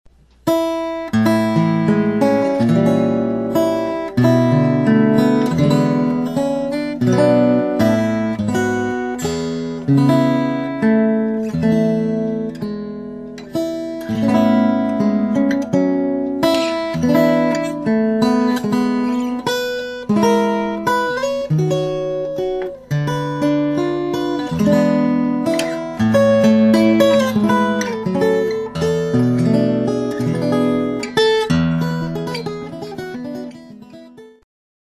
guitar solo with great chords and voice movement